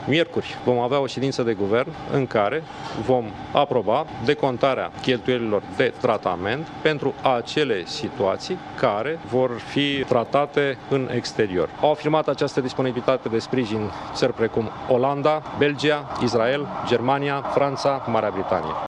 Premierul interimar, Sorin Câmpeanu, a precizat că, deşi există şi în spitalele din România competenţele medicale şi dotările necesare pentru recuperarea răniţilor, unii pacienţi au ales să fie trataţi în străinătate: